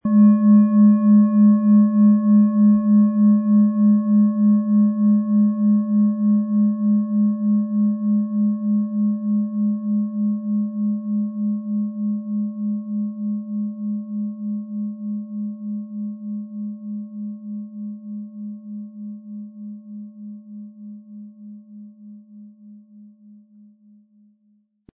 MaterialBronze